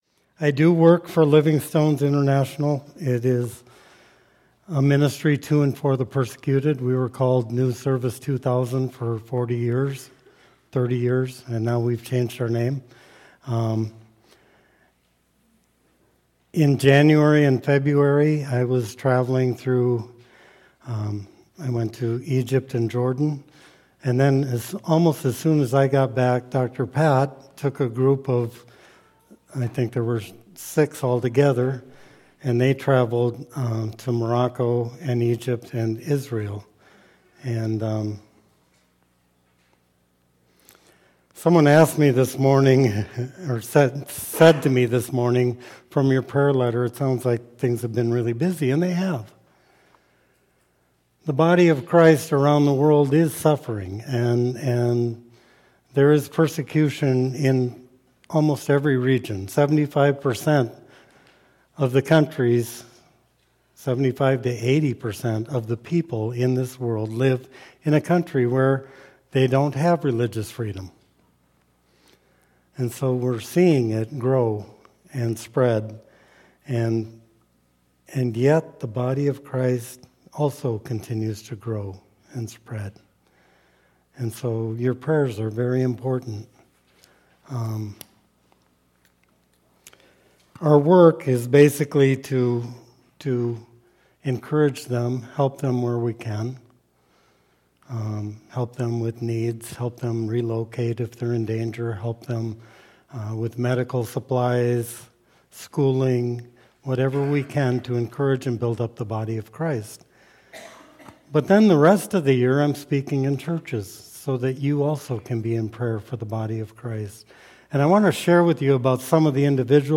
A challenging and encouraging message about suffering and the persecuted church by guest speaker